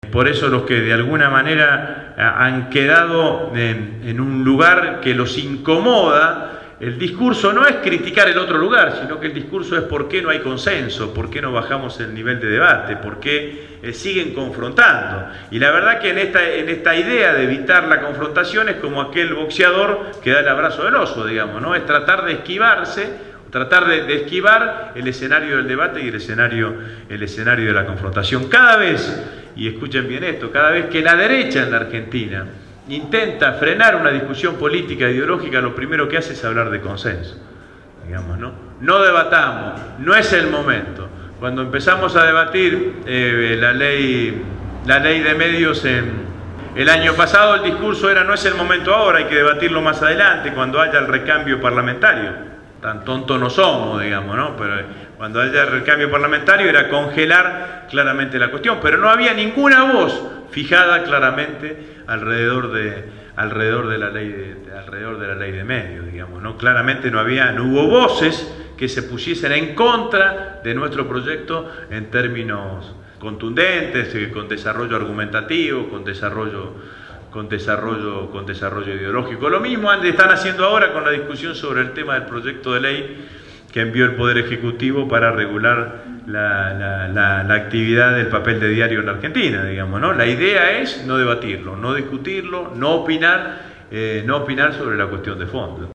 El último 4 de Octubre, organizado por la agrupación «Todas con Cristina«, estuvo presente en el espacio de la Radio Gráfica Agustín Rossi, Presidente del Bloque del FPV en la cámara baja.
Casi dos horas de charla-debate sirvieron para dejar satisfecha a la concurrencia, responder inquietudes y plantear nuevos desafios